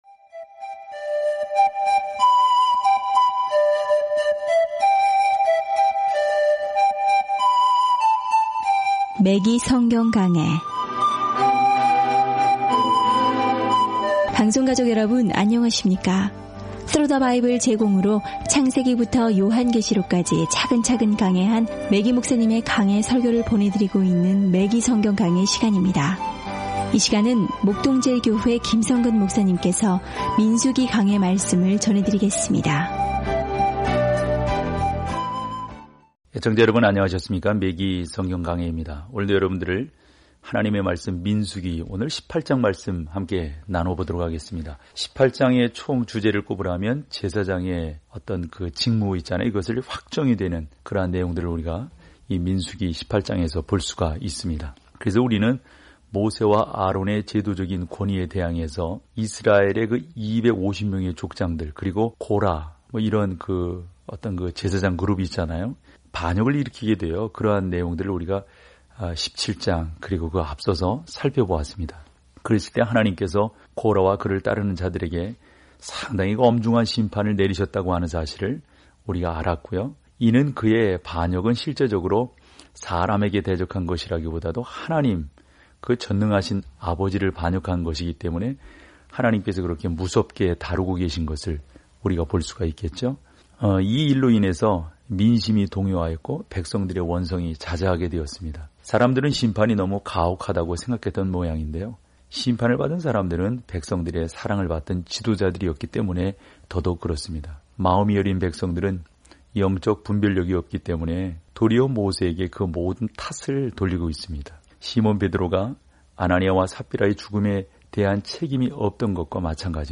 말씀 민수기 18 민수기 19 15 묵상 계획 시작 17 묵상 소개 민수기에서 우리는 광야 40년 동안 이스라엘과 함께 걷고, 방황하고, 예배하고 있습니다. 오디오 공부를 듣고 하나님의 말씀에서 선택한 구절을 읽으면서 매일 민수기를 여행하세요.